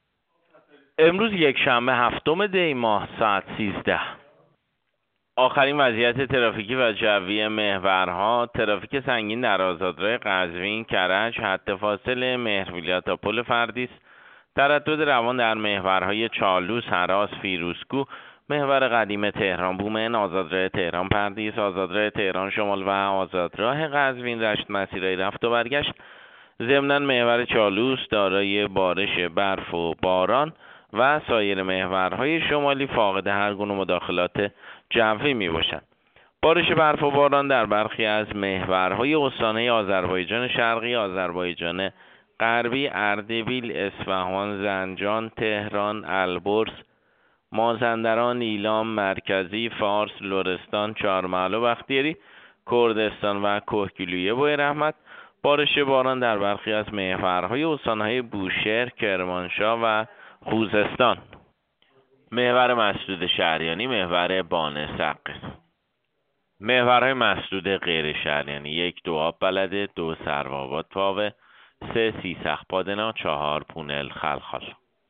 گزارش رادیو اینترنتی از آخرین وضعیت ترافیکی جاده‌ها ساعت ۱۳ هفتم دی؛